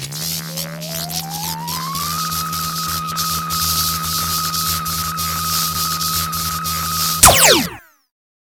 beamstart.wav